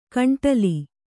♪ kaṇṭali